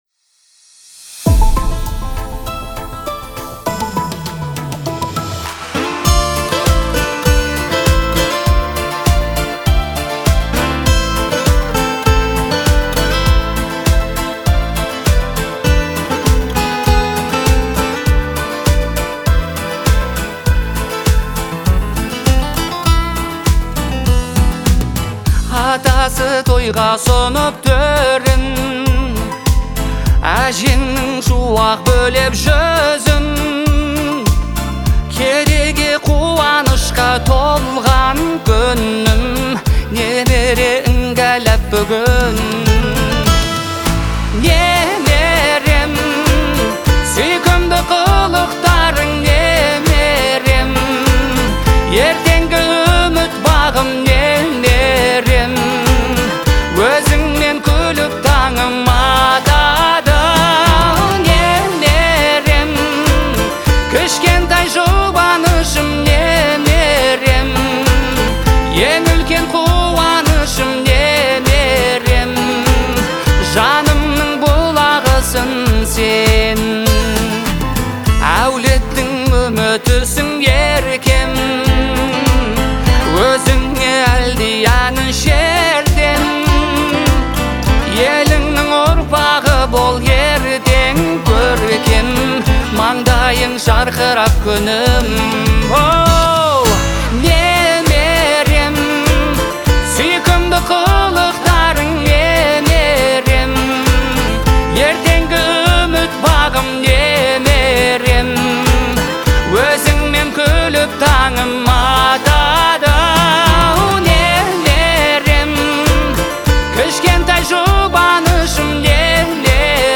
Трек размещён в разделе Поп / 2022 / Казахская музыка.